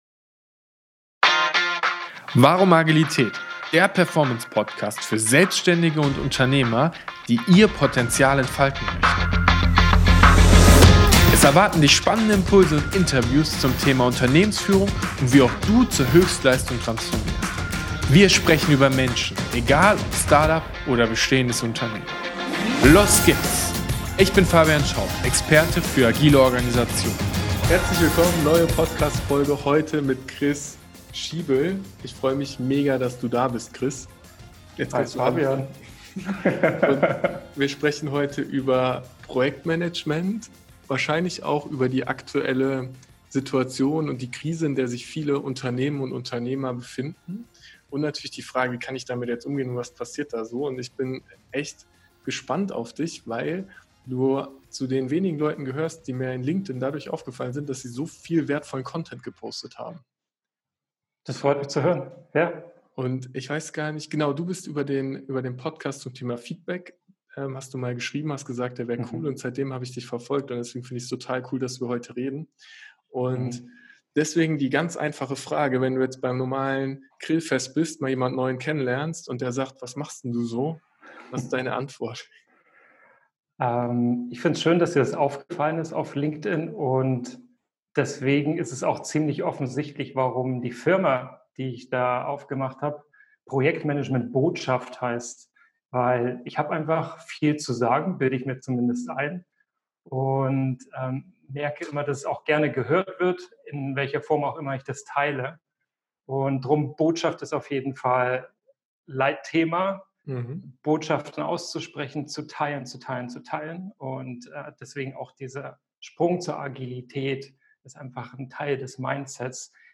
#028 Interview